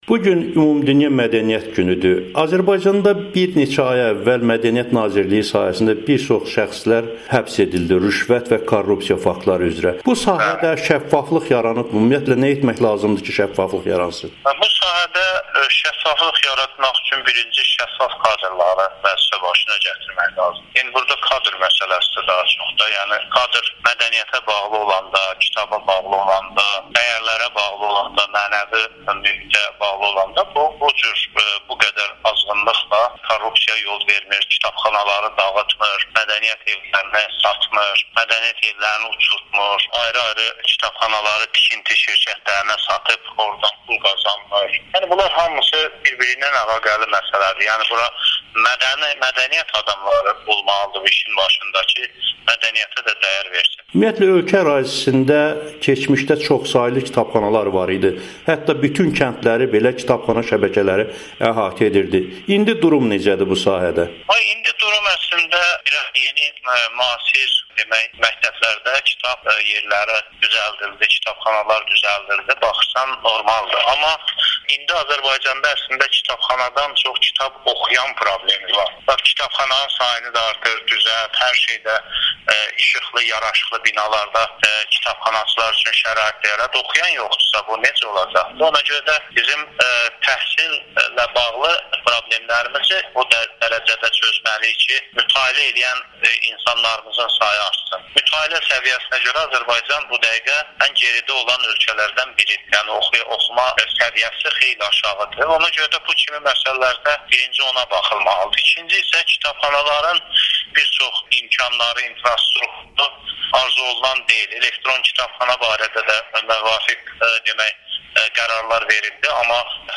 Azərbaycanda mədəniyyət sahəsində durum necədir? Bu mövzu ilə bağlı Milli Məclisin Mədəniyyət Komitəsinin sədr müavini Fazil Mustafa Amerikanın Səsinə müsahibəsində danışıb.